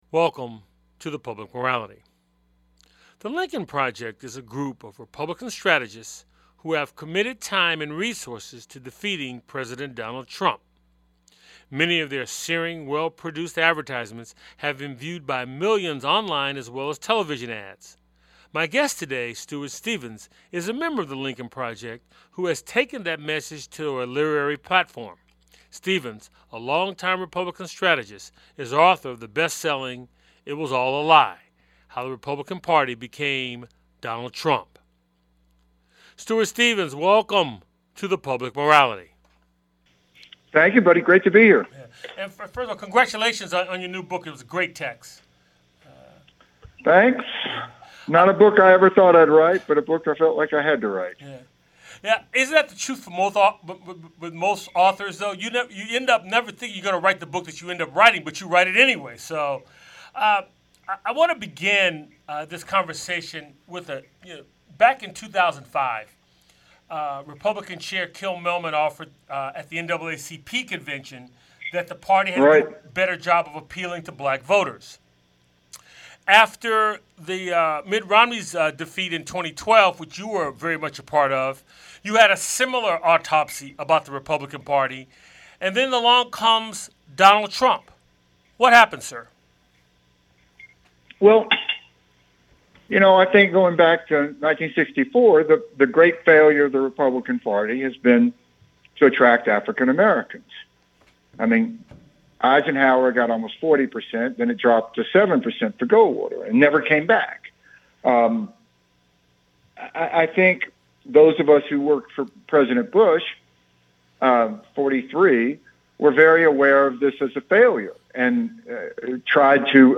Guest on this show is Stuart Stevens, Republican Political Consultant.&nbsp
It's a weekly conversation with guest scholars, artists, activists, scientists, philosophers and newsmakers who focus on the Declaration of Independence, the Constitution and the Emancipation Proclamation as its backdrop for dialogue on issues important to our lives. The show airs on 90.5FM WSNC and through our Website streaming Tuesdays at 7:00p.